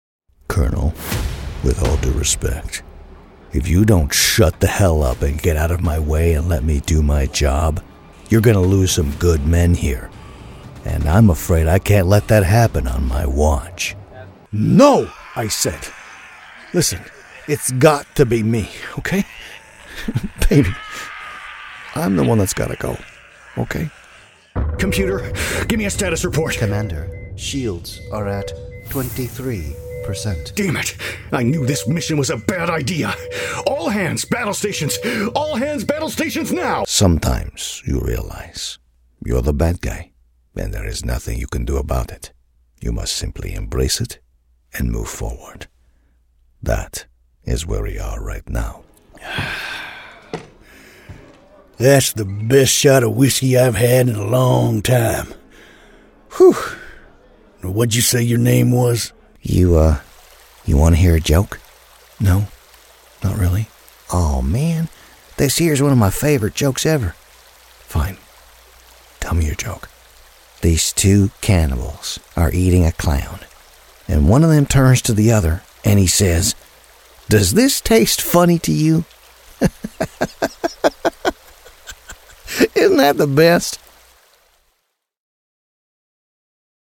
Easy-going, Guy-Next-Door, Conversational.
Video Game